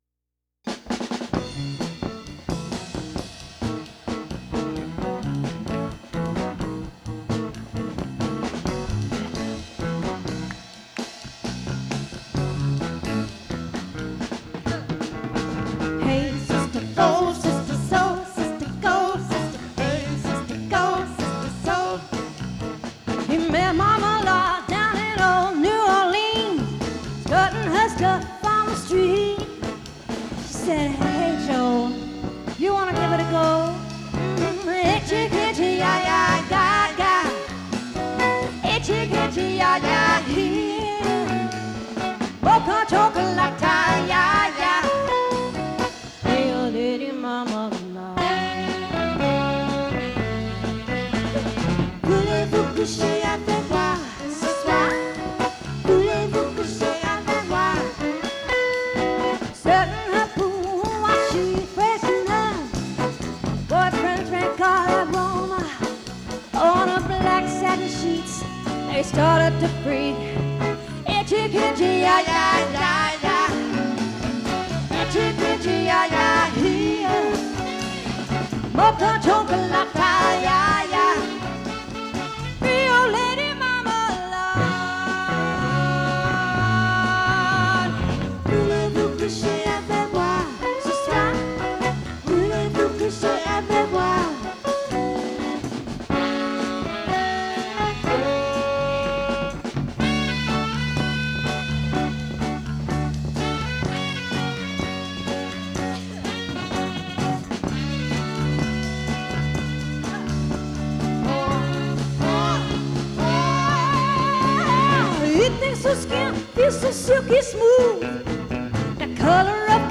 Jazz/Blues/Pop